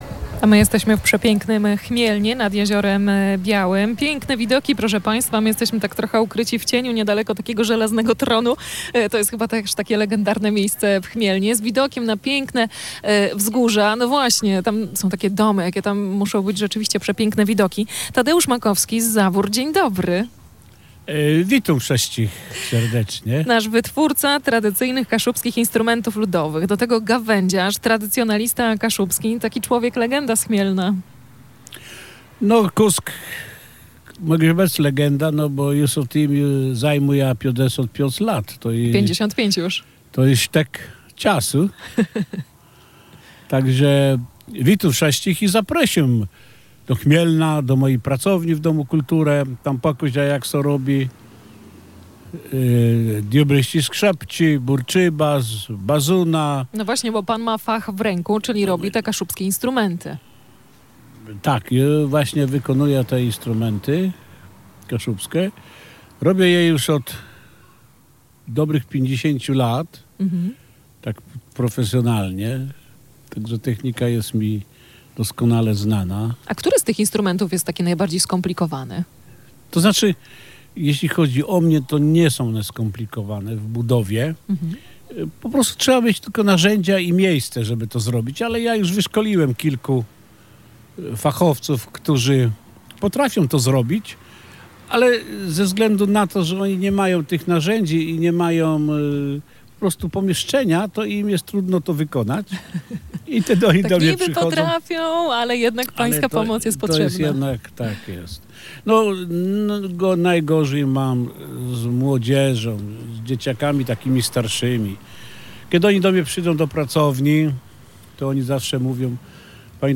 Wybraliśmy się w podróż za jeden uśmiech. Mobilne studio Radia Gdańsk nadawało z Chmielna